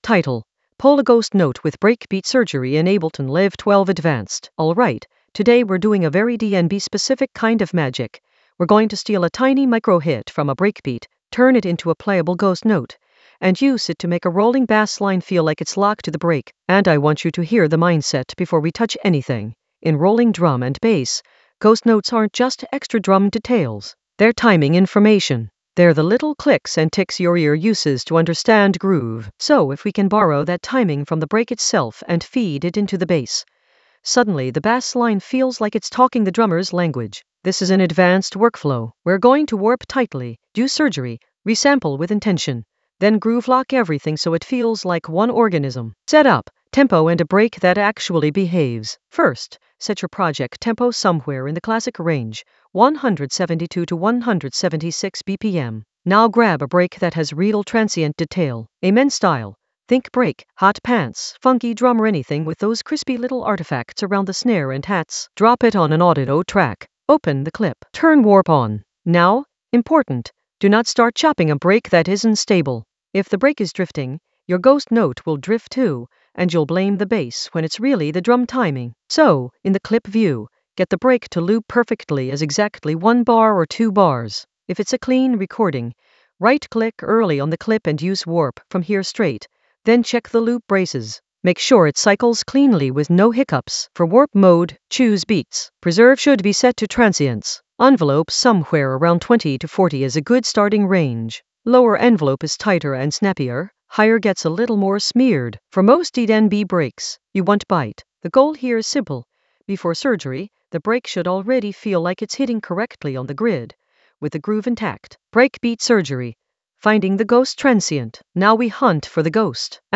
Narrated lesson audio
The voice track includes the tutorial plus extra teacher commentary.
An AI-generated advanced Ableton lesson focused on Pull a ghost note with breakbeat surgery in Ableton Live 12 in the Basslines area of drum and bass production.